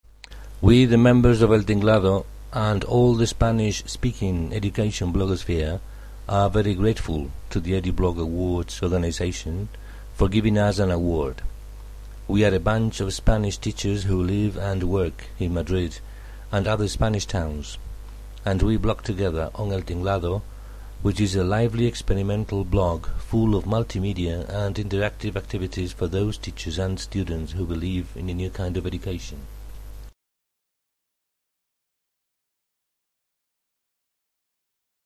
award_speech.mp3